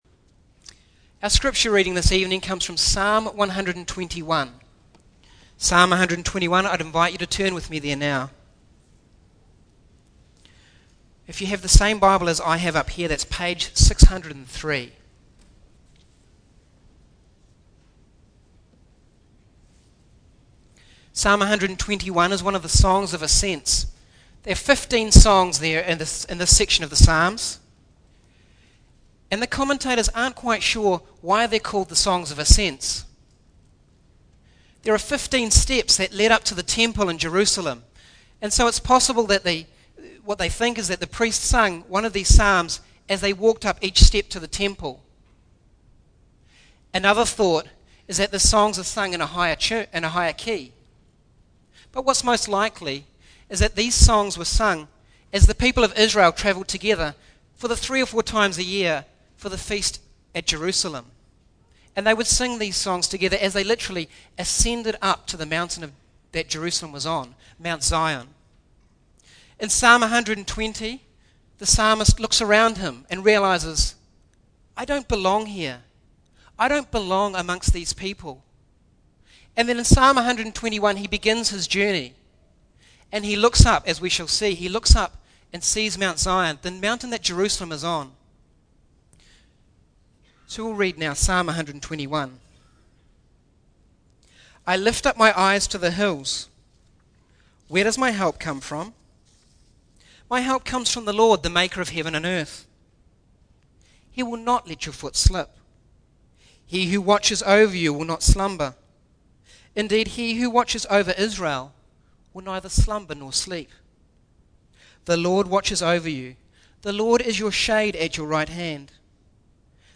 Single Sermons - Lynwood United Reformed Church - Page 29